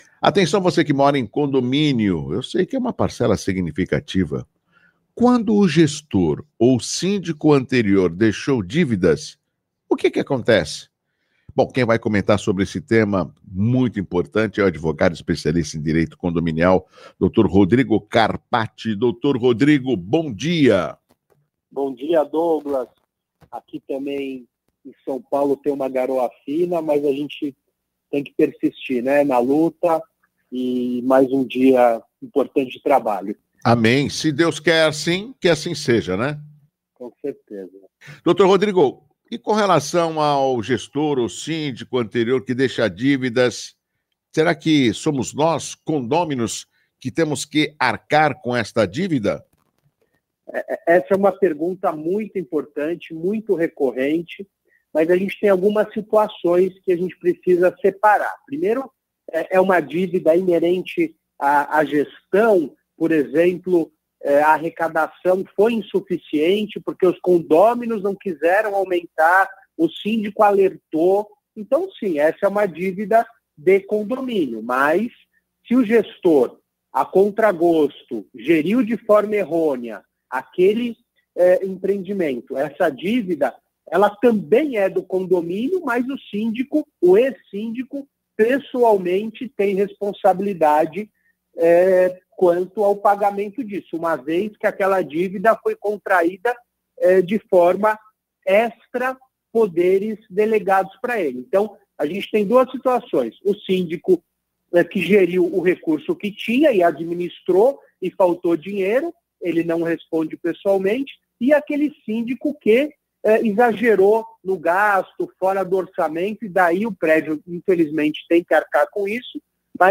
Em entrevista para a CBN de Santos